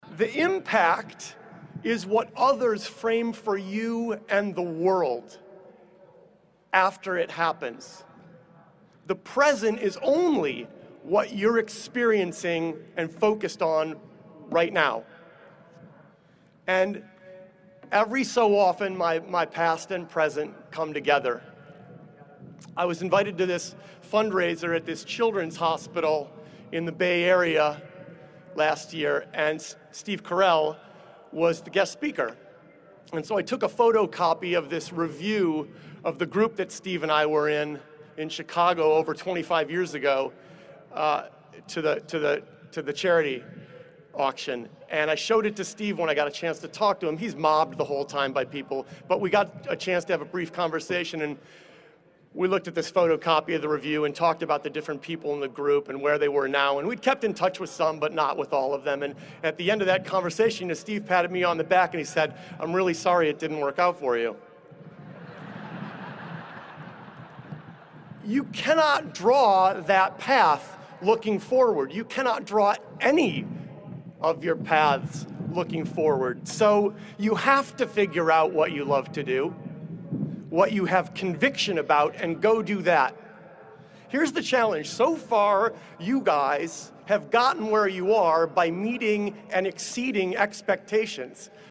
公众人物毕业演讲第330期:推特CEO迪克2013密歇根大学(8) 听力文件下载—在线英语听力室